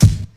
• Sharp Kick Single Hit G Key 328.wav
Royality free bass drum tuned to the G note. Loudest frequency: 712Hz
sharp-kick-single-hit-g-key-328-A9G.wav